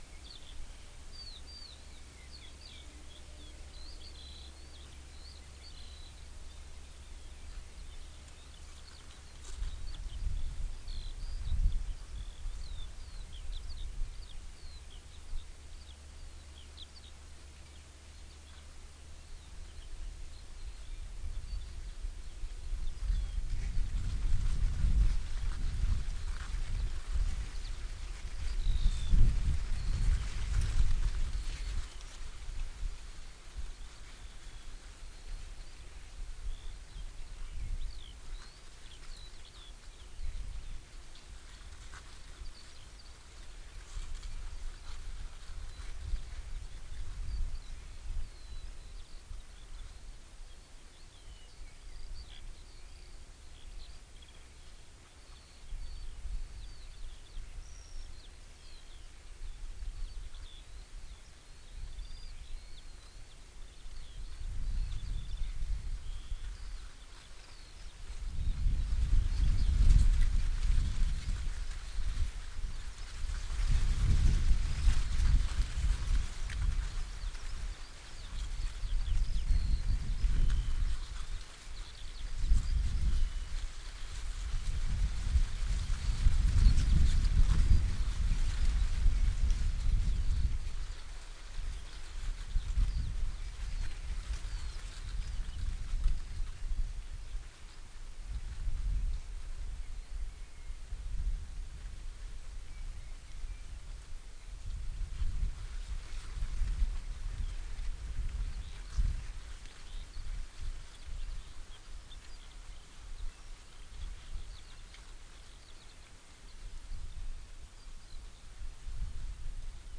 Corvus corax
Emberiza citrinella
Alauda arvensis
Turdus merula